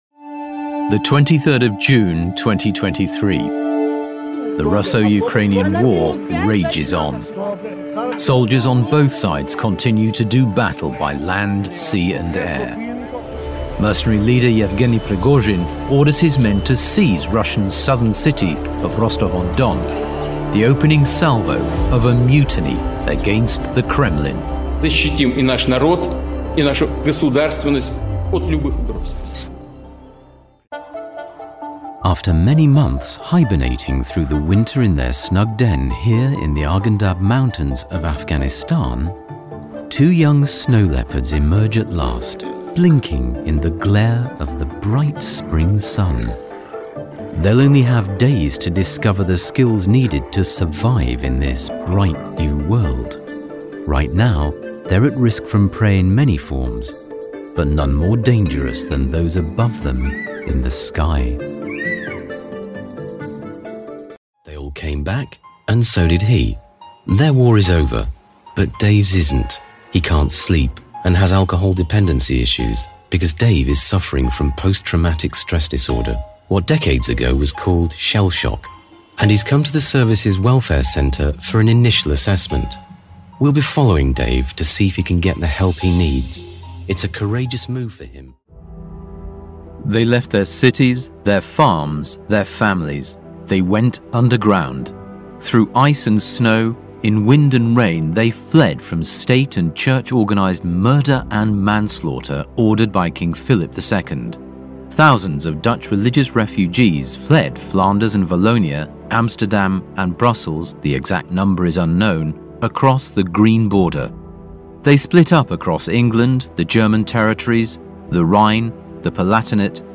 Male
Current, versatile, engaging, rich, warm.
Documentary
3 Differing Tones
Words that describe my voice are Conversational, Natural, Versatile.
0221Documentary_reel.mp3